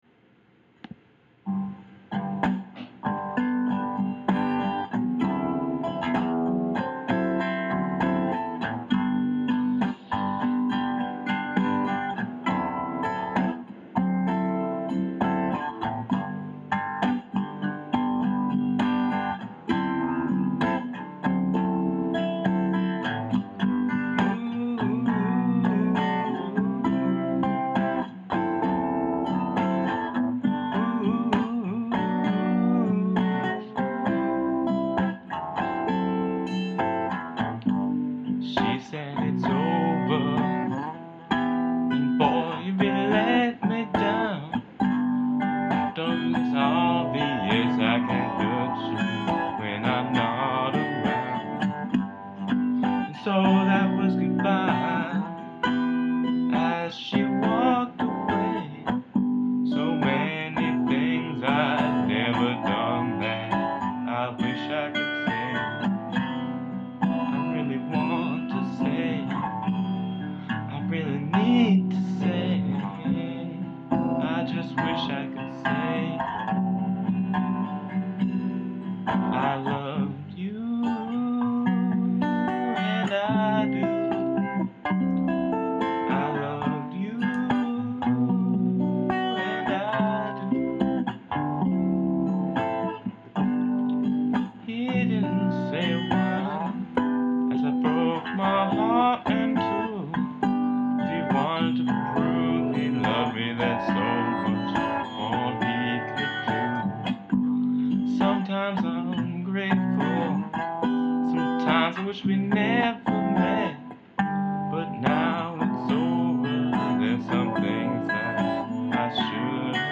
i-loved-you-extremely-rough-demo.mp3